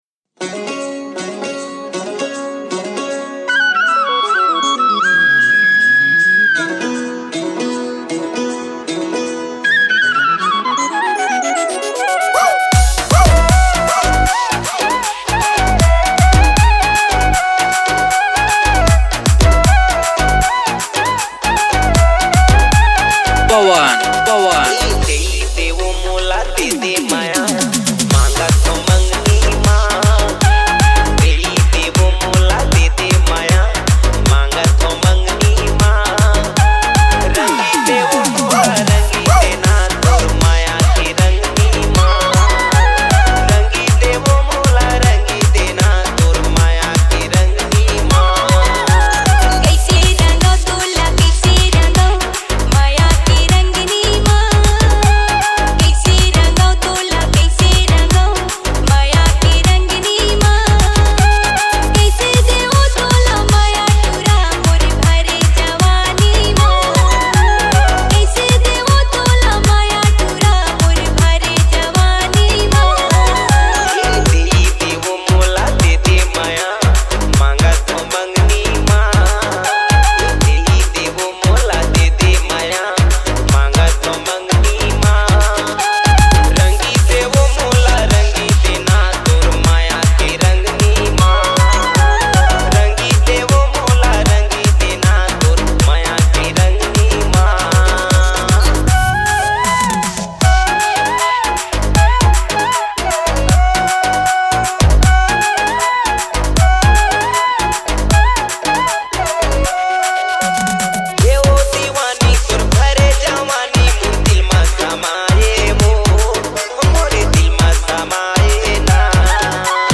Gold And Old DJ song